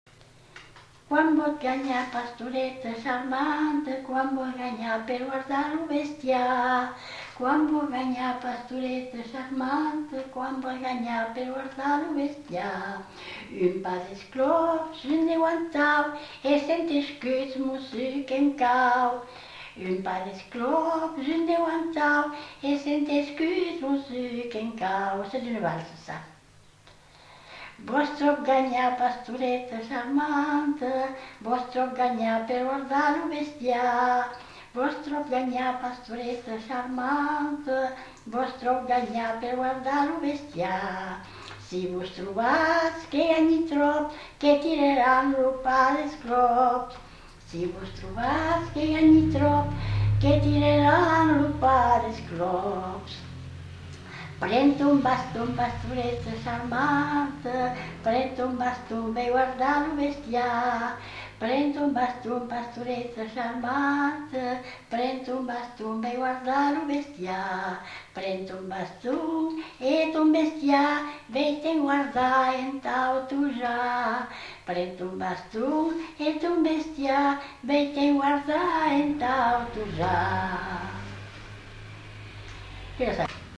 Aire culturelle : Gabardan
Genre : chant
Effectif : 1
Type de voix : voix de femme
Production du son : chanté
Danse : valse